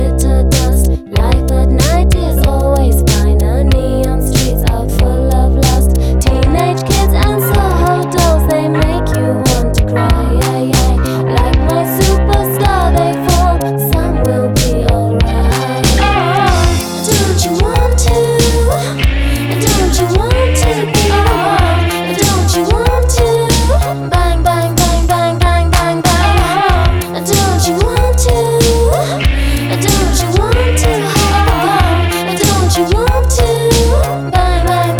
Жанр: Поп музыка / Рок / Электроника